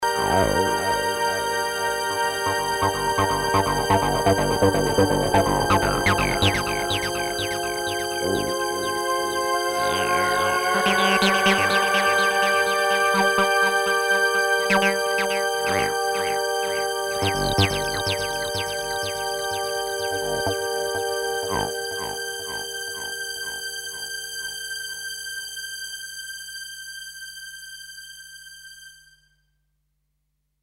Думаю это совершенно конкретный инструмент играет.